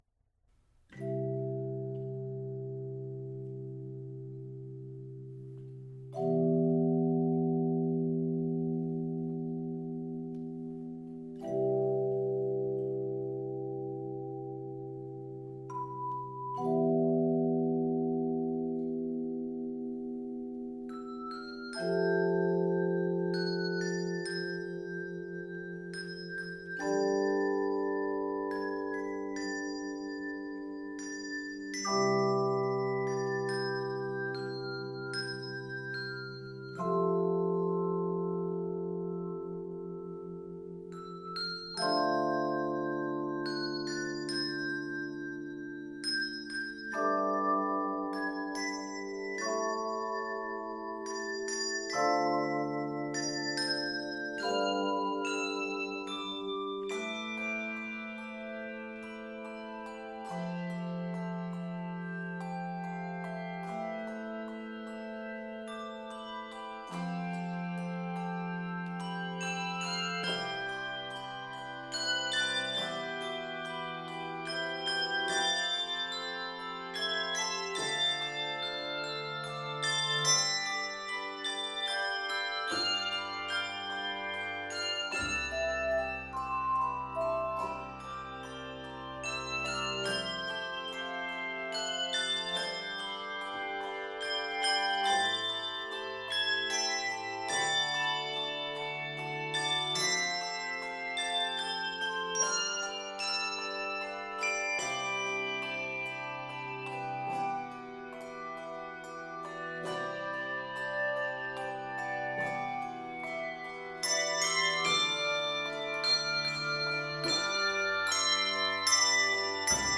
Flowing and expressive